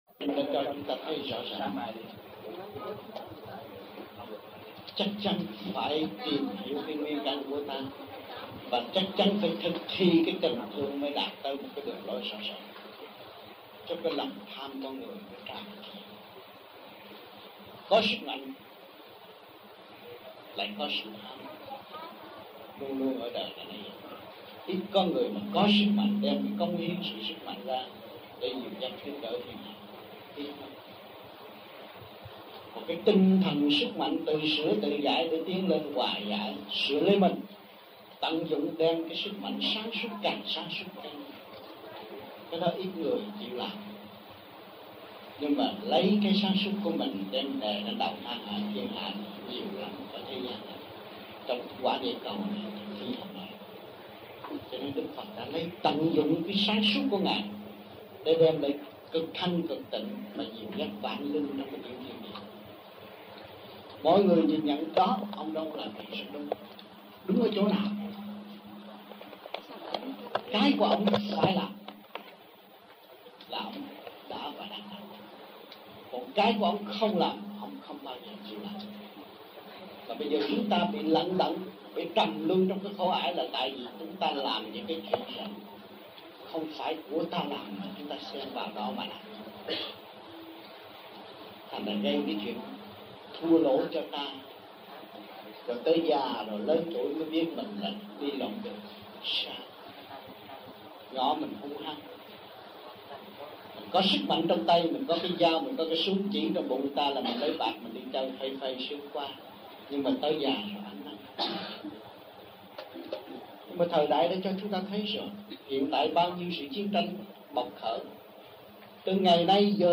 1975-03-30 - SÀI GÒN - THUYẾT PHÁP 2